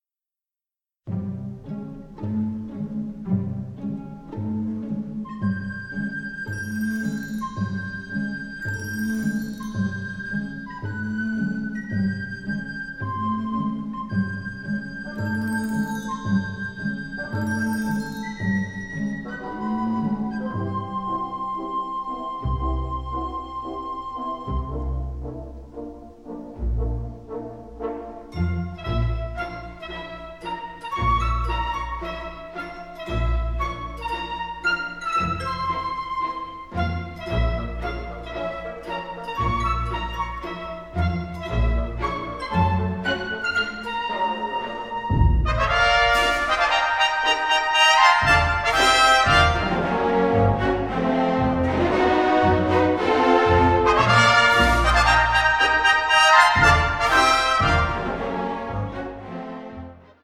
Film score